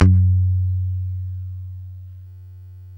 Index of /90_sSampleCDs/Roland - Rhythm Section/BS _E.Bass 2/BS _Rock Bass